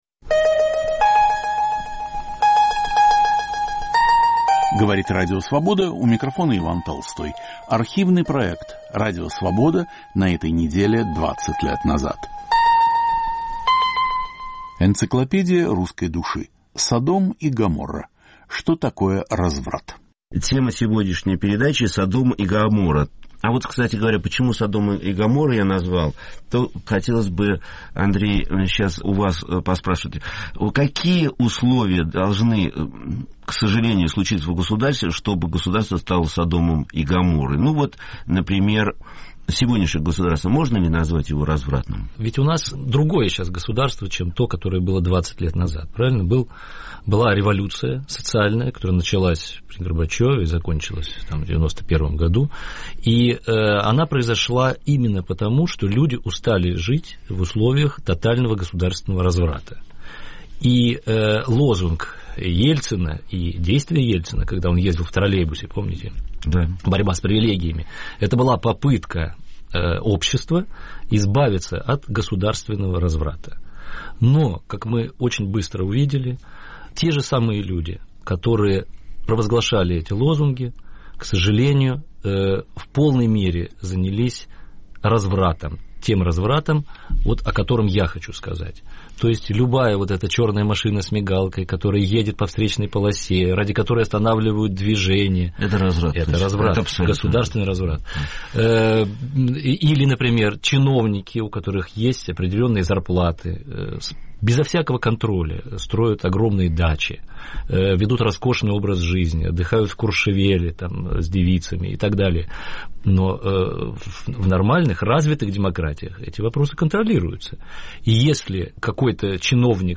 Понятие разврата в разных культурах и в представлениях каждого человека. В студии социолог Игорь Кон и литератор Андрей Мальгин. Автор и ведущий Виктор Ерофеев.